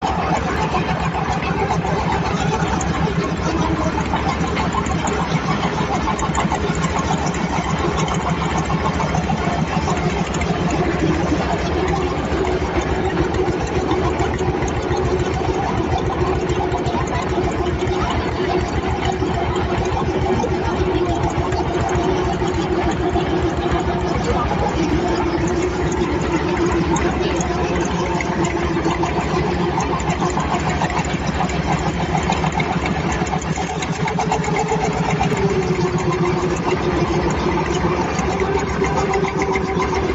Tiếng Đò máy, Thuyền máy… chạy trên sông Miền Tây
Thể loại: Tiếng xe cộ
Description: Tiếng đò máy, ghe máy, xuồng máy nổ "cật cật" vang vọng giữa dòng sông phù sa – âm thanh đặc trưng, quen thuộc của miền Tây sông nước. Những nhịp máy nổ đều đều trên những con rạch ngoằn ngoèo, len lỏi qua xóm làng, bến bãi.
tieng-do-may-thuyen-may-chay-tren-song-mien-tay-www_tiengdong_com.mp3